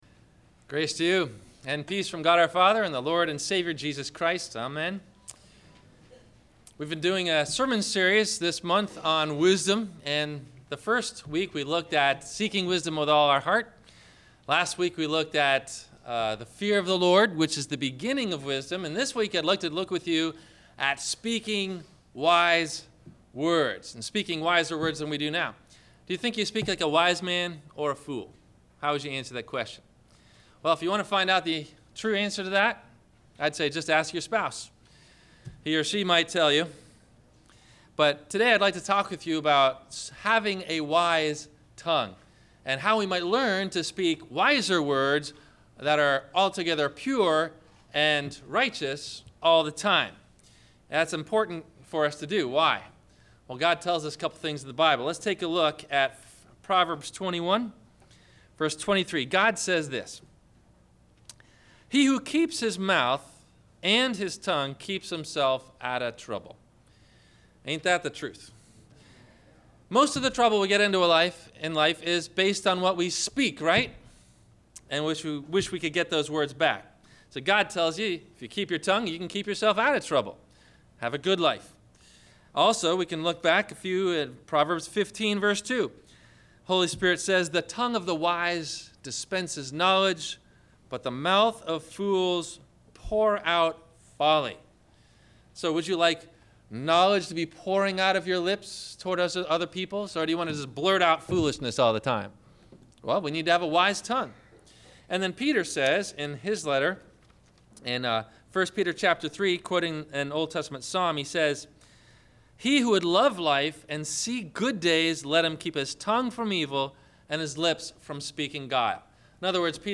Do You Speak Like A Wise Man, or A Fool? – Sermon – January 25 2015